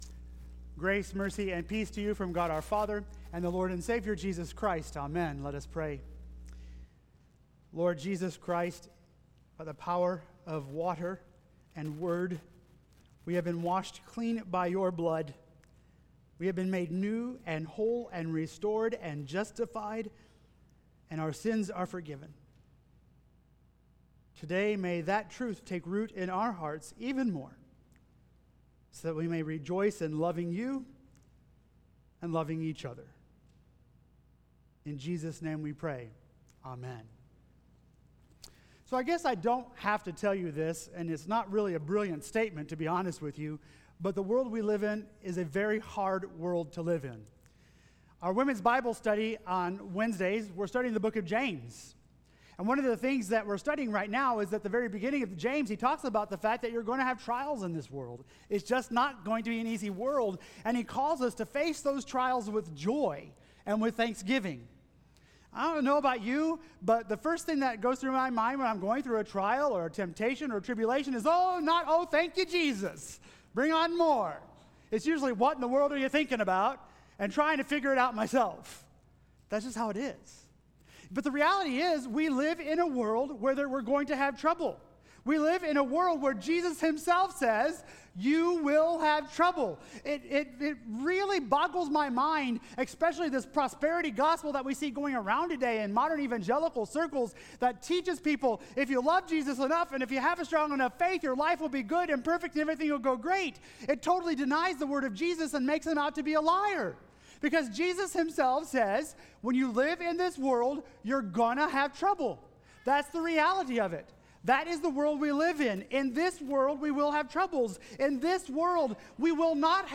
The Message The Holy Spirit, the Water, and the Blood: Daily Renewing the Power of Baptism